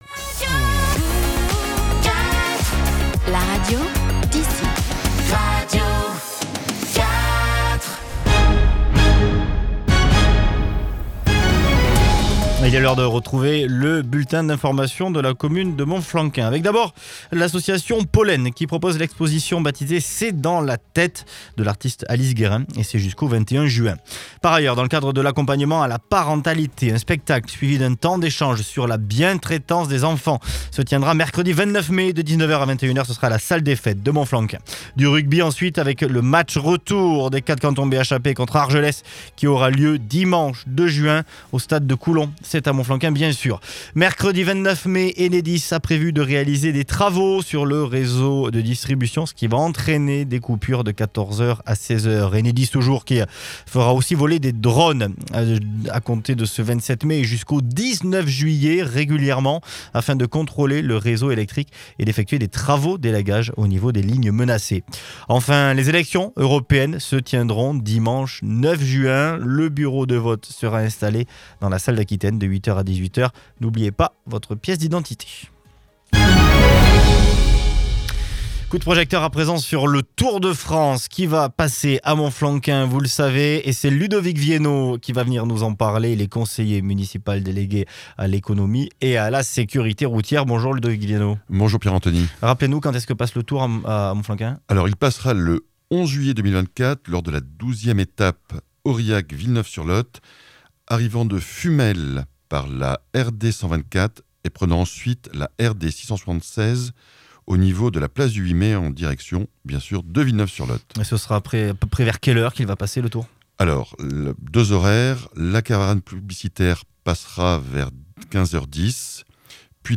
VOS ÉLU(E)S  à la RADIO